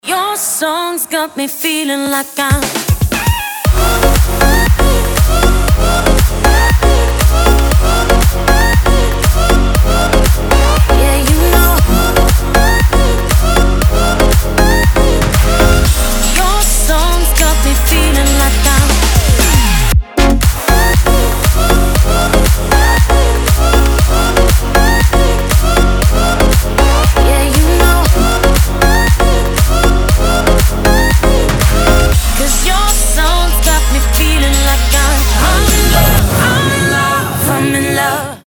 dance
club
house